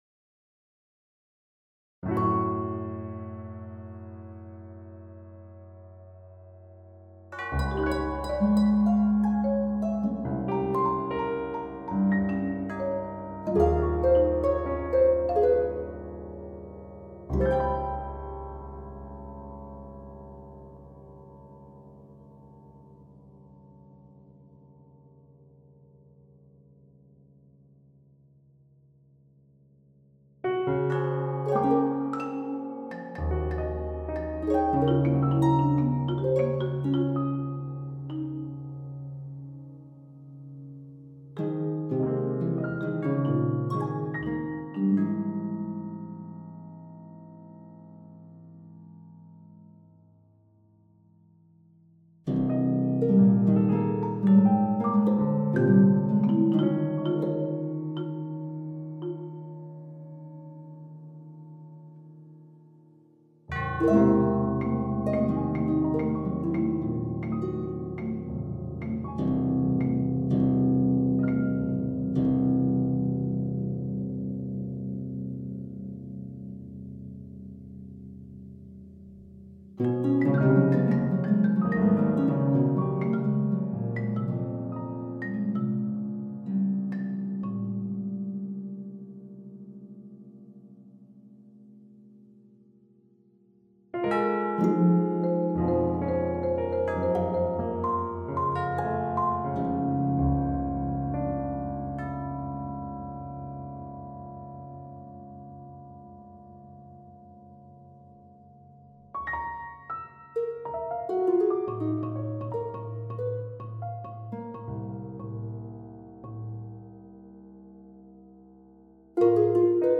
Three movements for tubular bells, marimba, harp and piano
Guide Recording